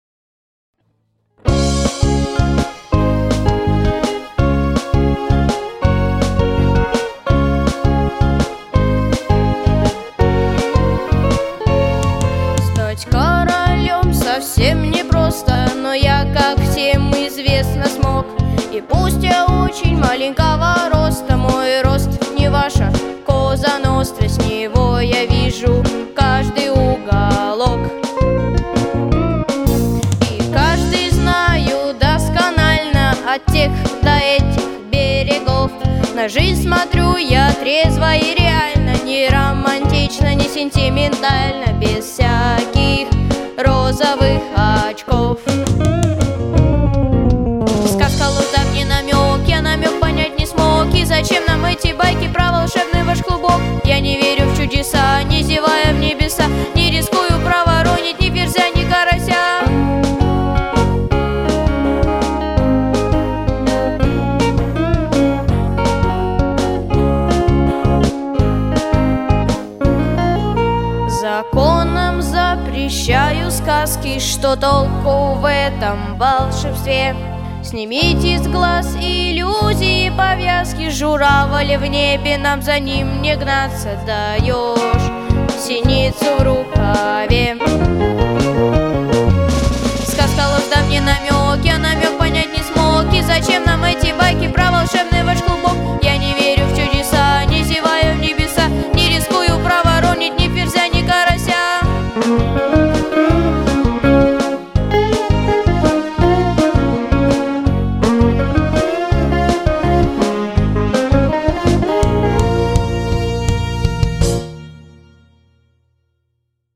Записано в студии Easy Rider в октябре–декабре 2023 года